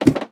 assets / minecraft / sounds / step / ladder3.ogg
ladder3.ogg